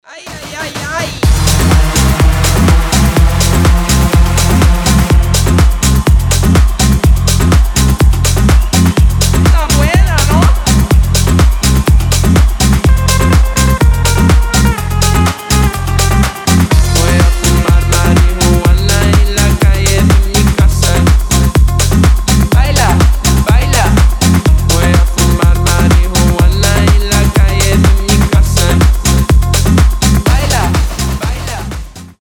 • Качество: 320, Stereo
ритмичные
заводные
house